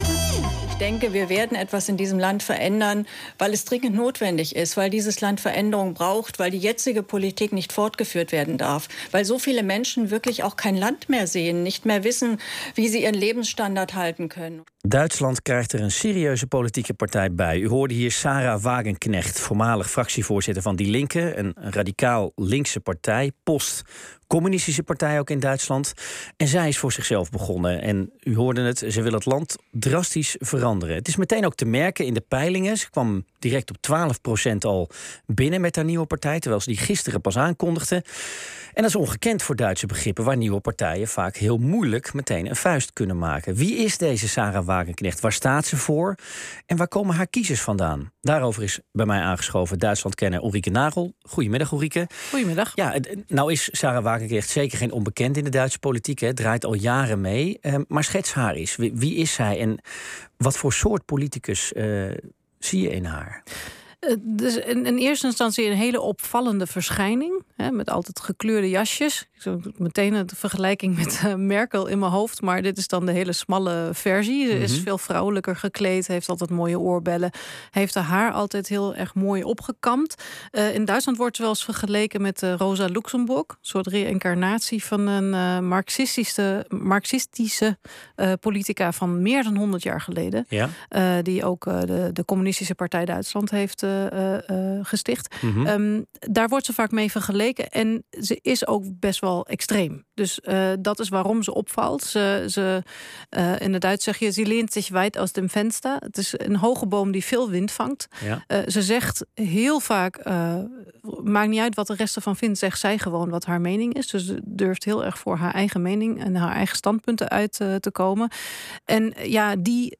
In Bureau Buitenland hoor je internationaal nieuws, reportages en geopolitiek.